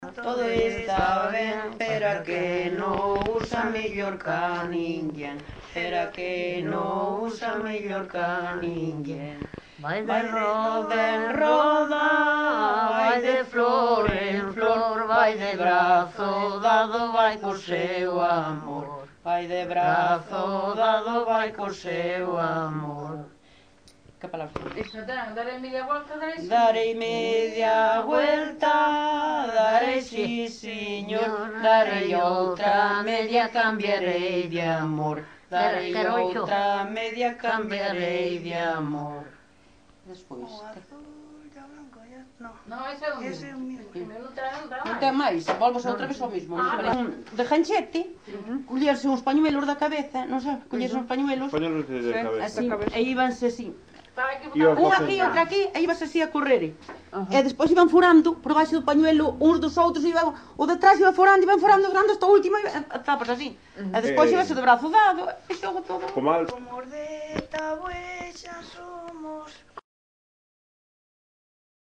Xogo de roda.
Tipo de rexistro: Musical
Soporte orixinal: Casete
Datos musicais Refrán
Instrumentación: Voz
Instrumentos: Voces femininas